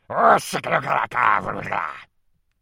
Звуки злости, ворчання